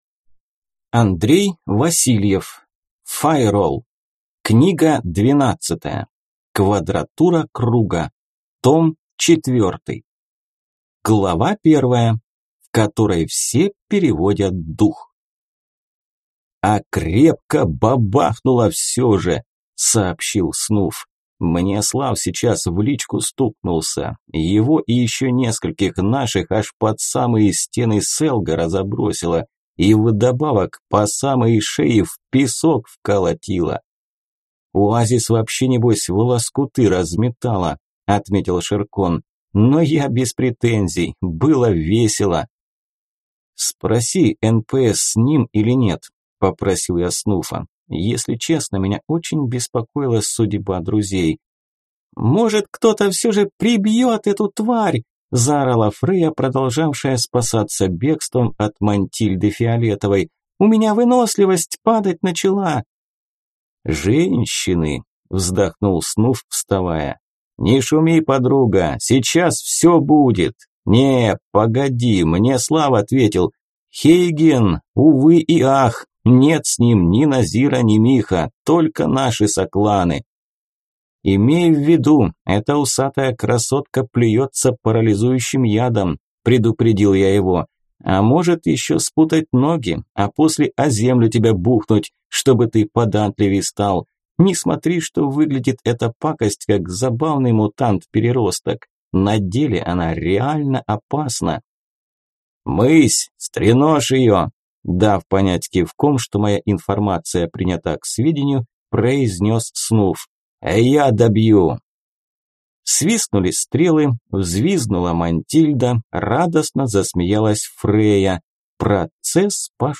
Аудиокнига Файролл. Квадратура круга. Том 4 | Библиотека аудиокниг